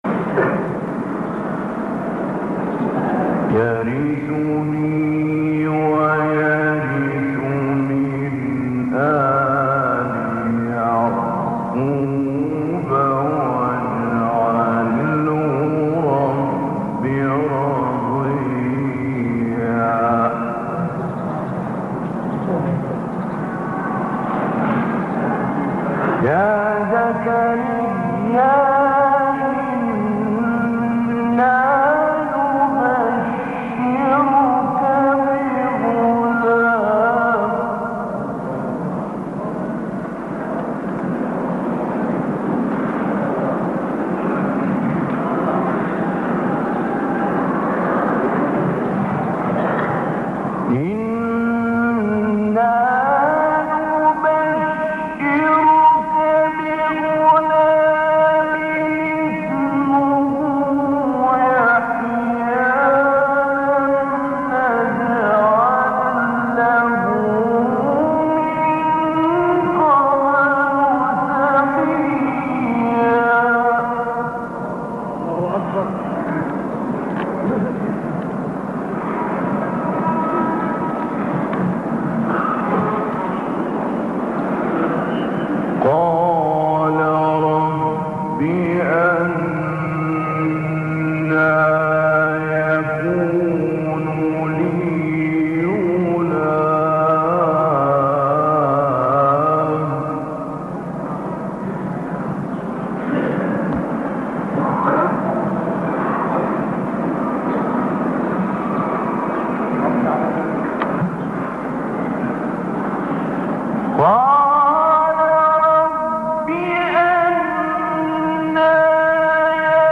گروه شبکه اجتماعی: نغمات صوتی با صدای قاریان برجسته مصری ارائه می‌شود.